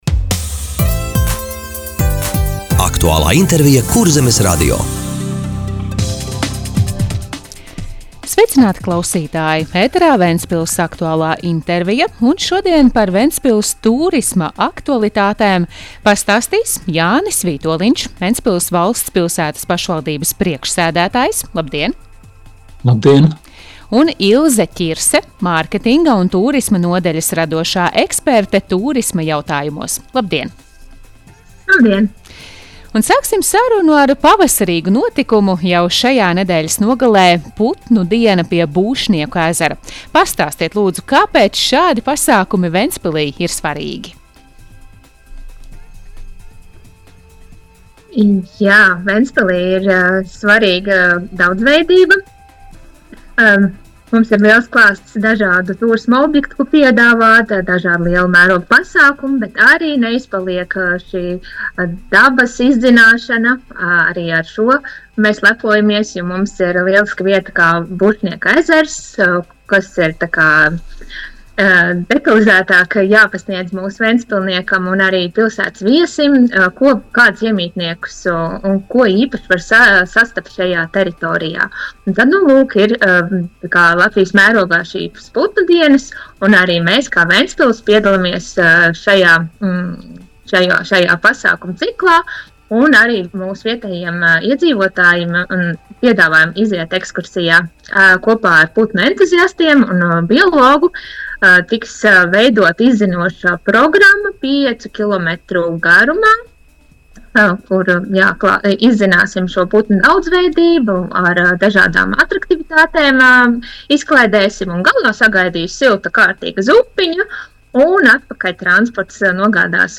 Radio saruna Ventspils tūrisma aktualitātes - Ventspils